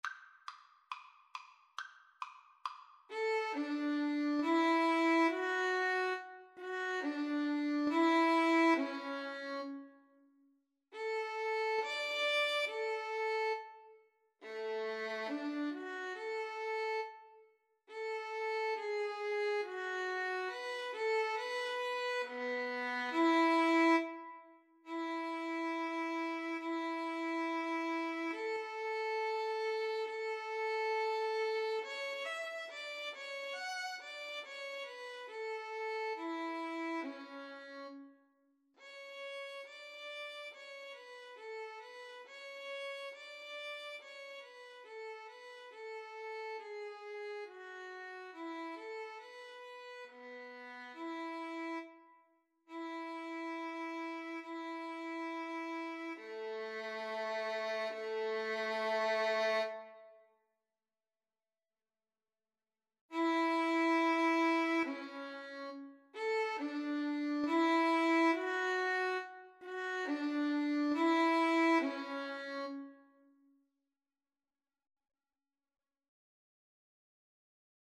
2/4 (View more 2/4 Music)
Andantino = c.69 (View more music marked Andantino)